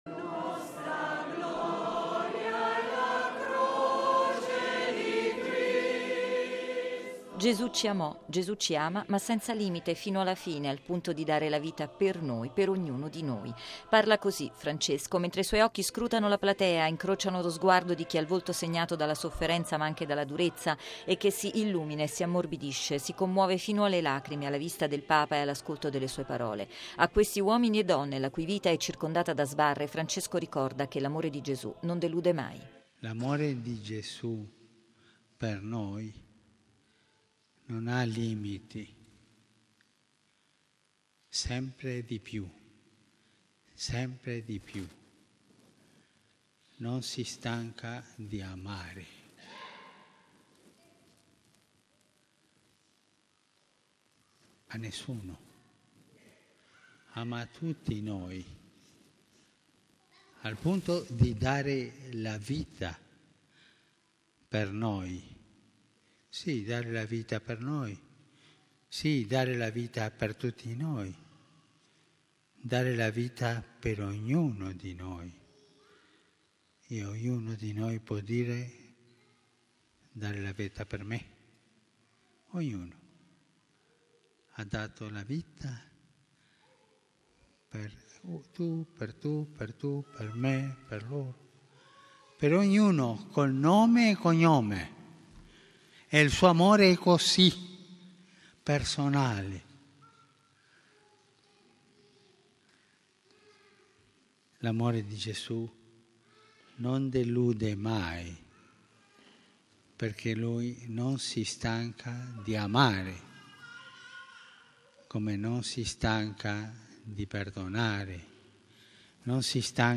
E’ la forte commozione che ha segnato la Messa in Coena Domini, inizio del Triduo pasquale, che il Papa ha celebrato nel carcere romano di Rebibbia, dove ha lavato e baciato i piedi a 12 detenuti, sei uomini e sei donne, tra stranieri e italiani.